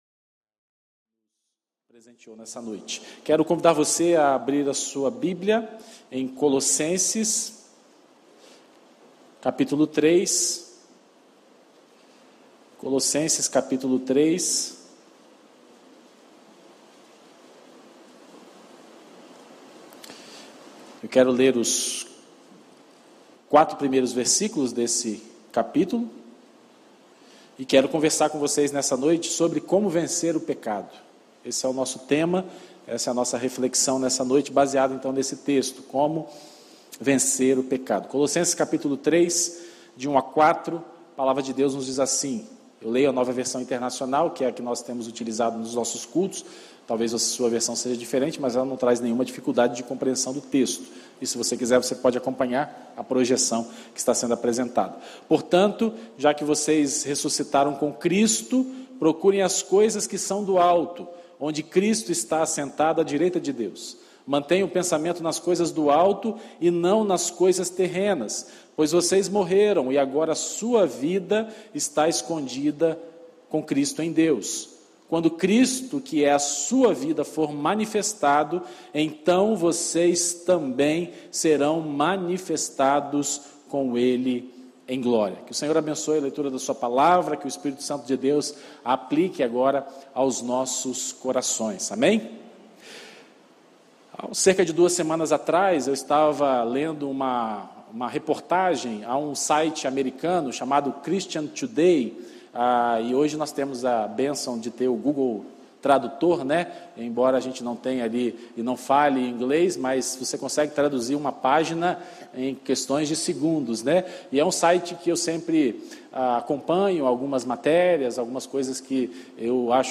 Mensagem apresentada
Primeira Igreja Batista do IPS